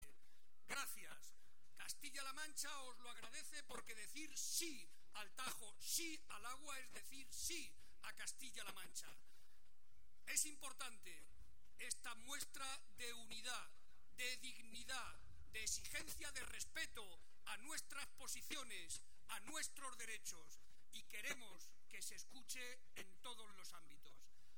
Barreda, que hoy participó en la concentración que se celebró en Talavera de la Reina (Toledo) en defensa del río Tajo, intervino ante las más de 15.000 personas que asistieron para recordar que siempre estará dispuesto, con la cabeza bien alta, “a defender nuestros intereses diciendo lo mismo en Toledo que en Madrid, en las Cortes regionales que en el Congreso de los Diputados, aquí y en Murcia, aquí y en Valencia”.